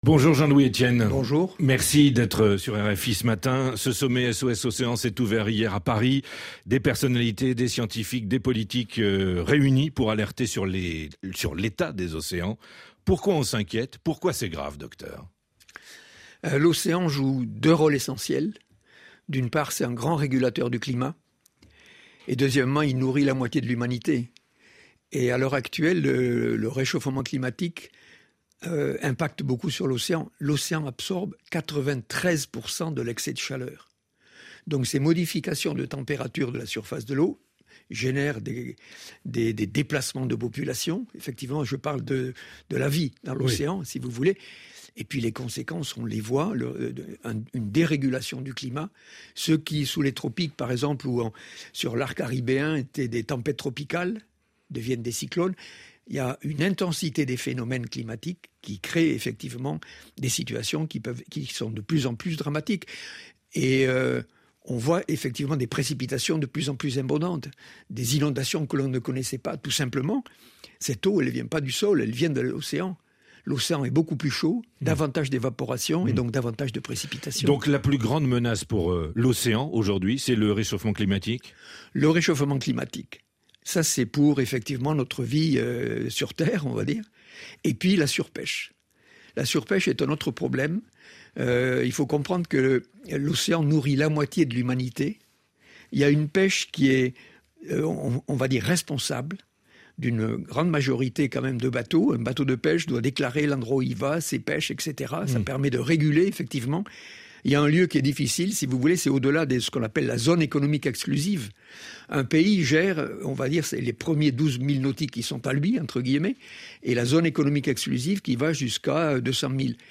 François Wu, vice-ministre taïwanais des Affaires étrangères, est l'invité de RFI ce 20 mars.
Il détaille la stratégie taïwanaise pour dissuader la Chine et ses attentes vis-à-vis des États-Unis de Donald Trump. Entretien.